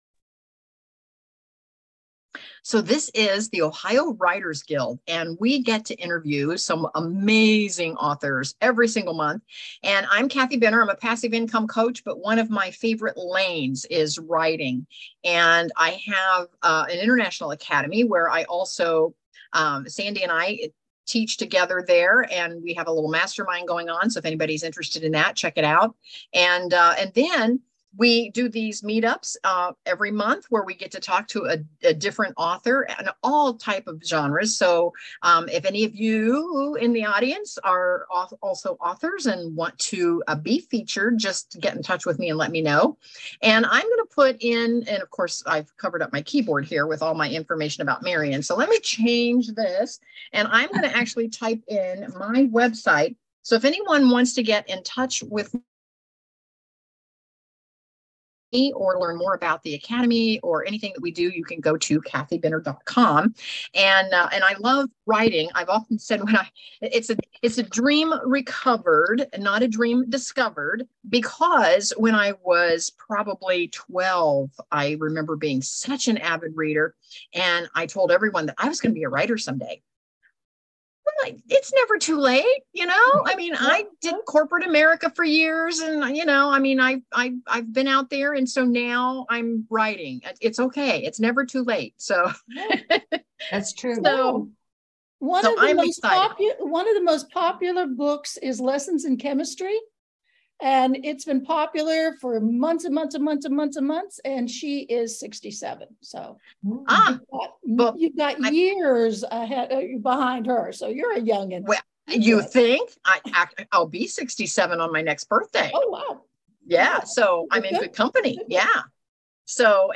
Ohio Writers' Guild | Interview